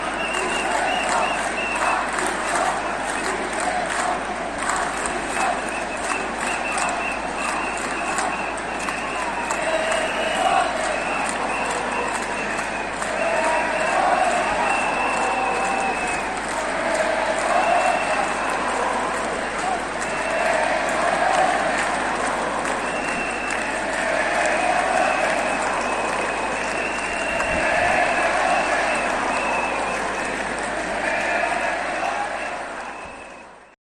Cánticos en la manifestación